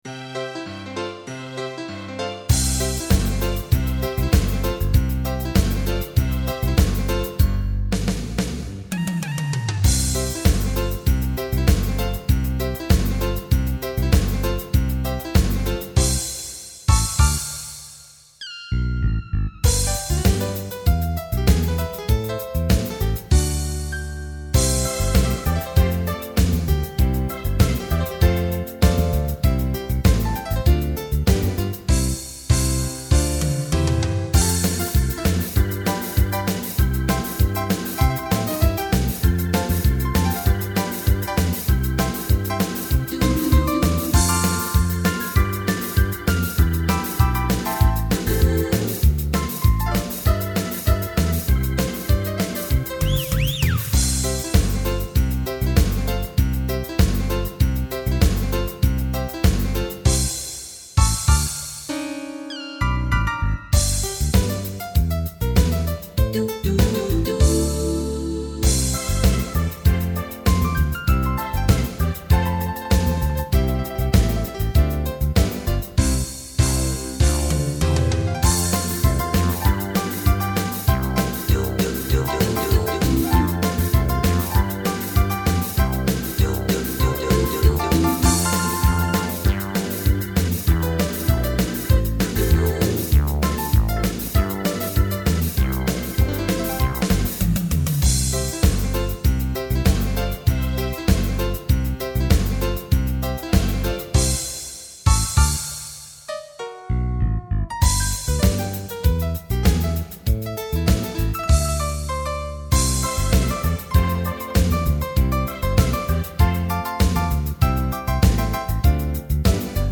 минусовка версия 48282